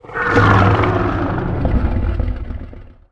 sound / monster2 / fire_dragon / attack2_1.wav
attack2_1.wav